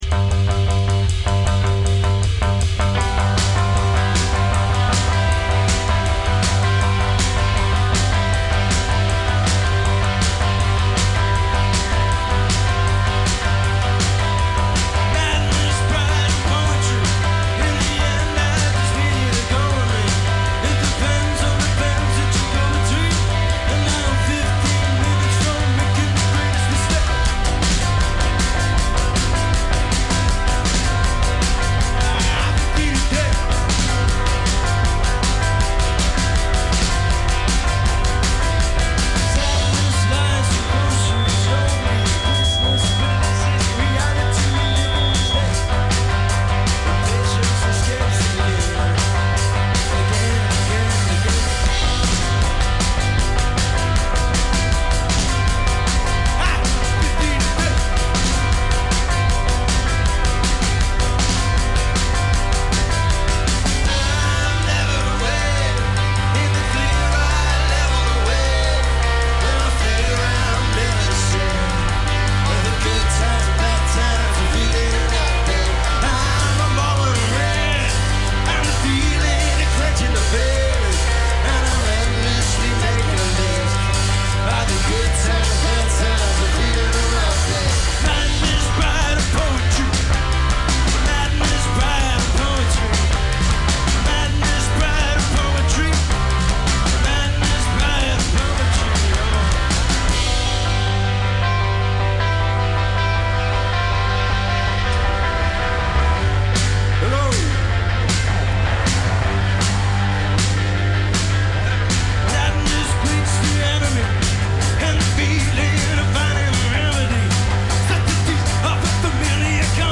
DIY ‘post-punk’ sound
five-piece band